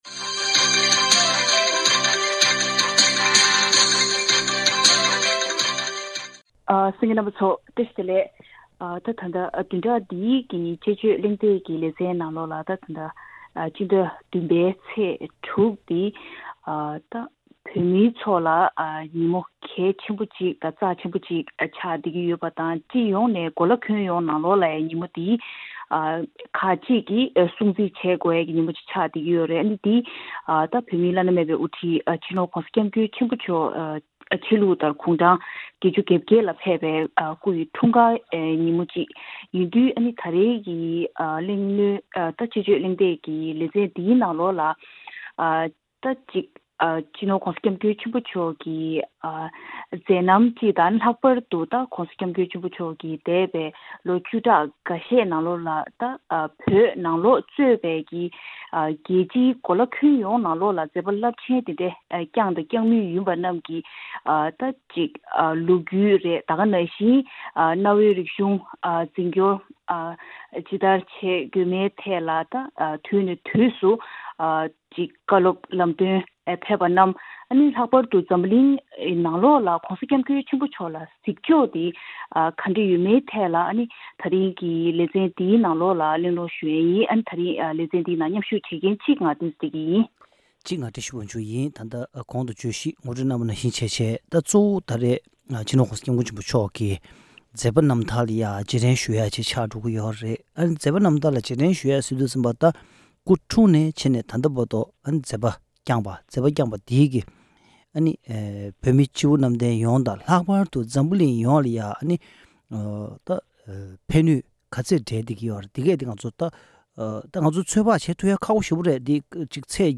བདུན་རེའི་དཔྱད་བརྗོད་ཀྱི་གླེང་སྟེགས་ལས་རིམ་ནང་།